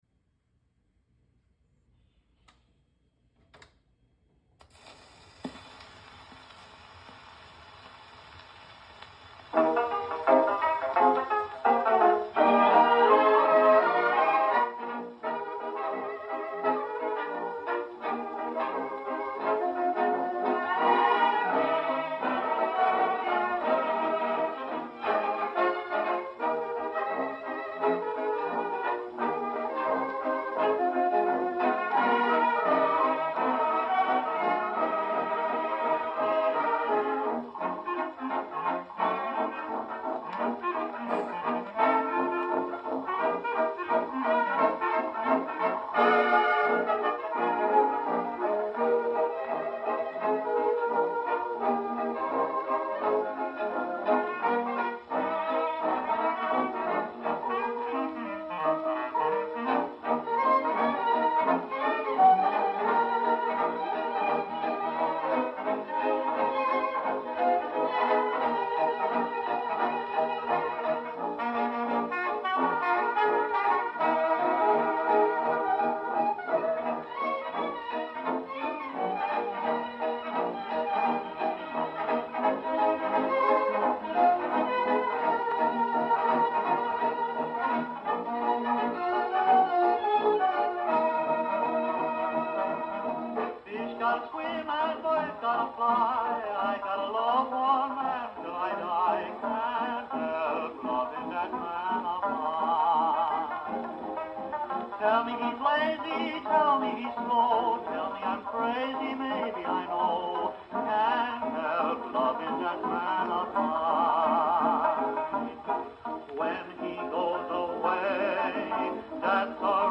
Recorded in New York City on December 15, 1927. #78rpm